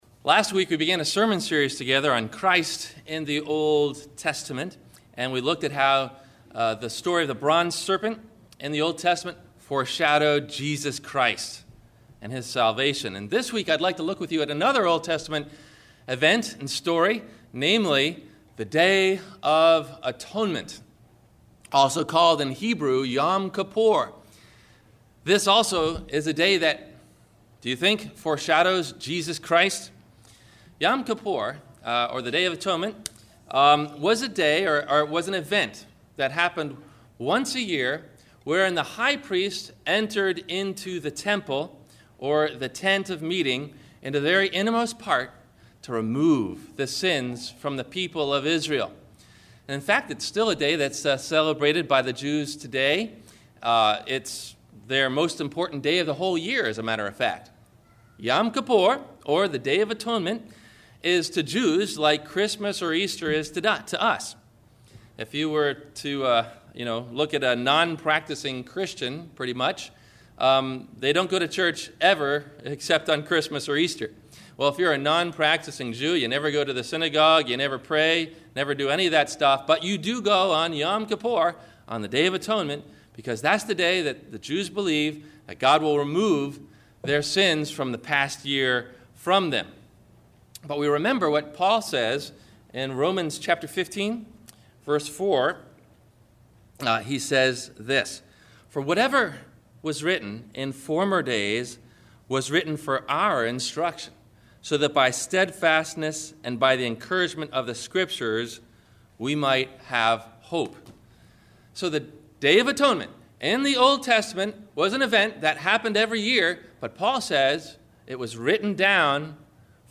The Day of Atonement – Sermon – April 22 2012